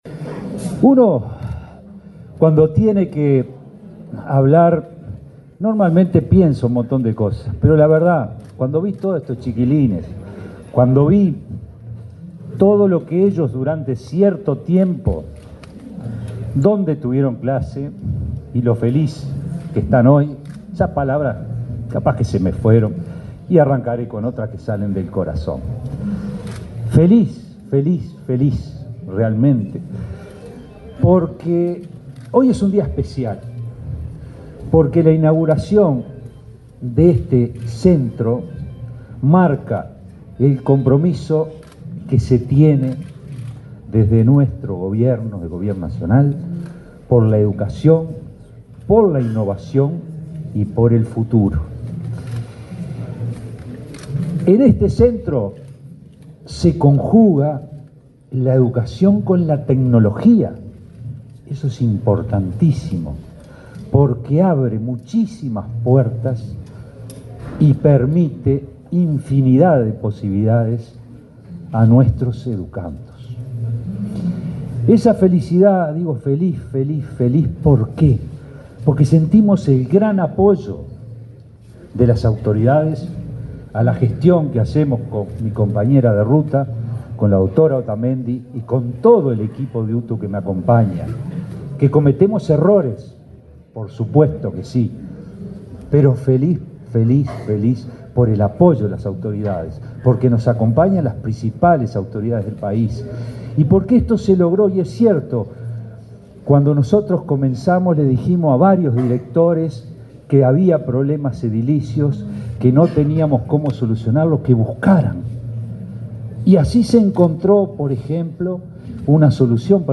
Palabra de autoridades de la ANEP 08/08/2024 Compartir Facebook X Copiar enlace WhatsApp LinkedIn El director general de UTU, Juan Pereyra, y la presidenta de la ANEP, Virginia Cáceres, hicieron uso de la palabra en el acto de inauguración de un centro de informática de ese instituto técnico, en el centro de Montevideo.